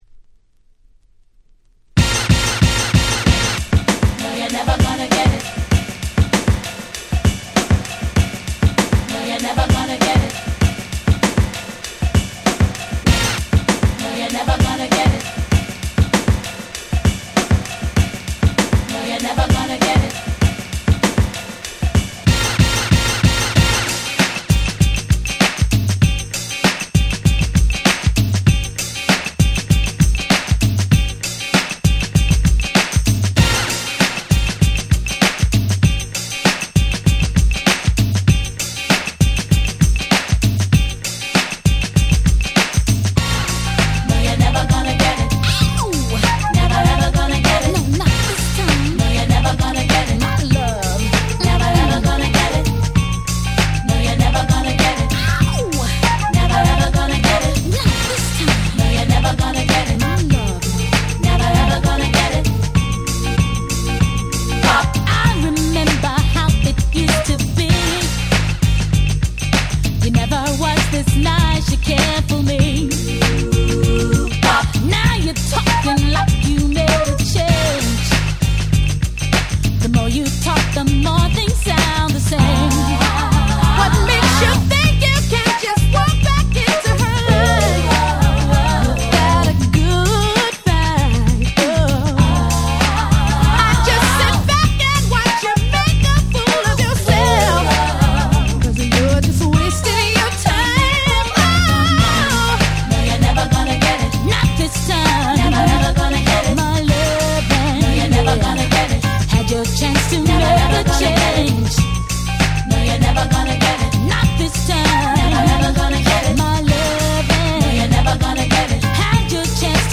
92' Super Hit R&B !!
彼女達らしい色っぽいダンスナンバー！